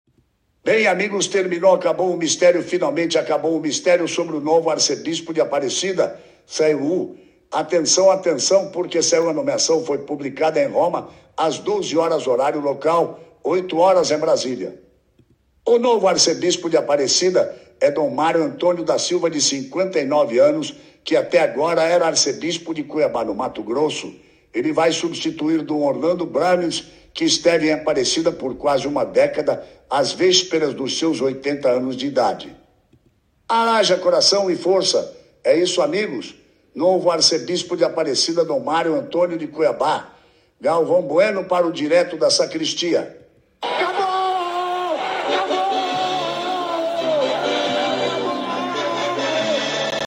Imaginemos como Galvão Buenos anunciaria essa nomeação, expressando
somente como ele toda a emoção da espera e da importância dessa decisão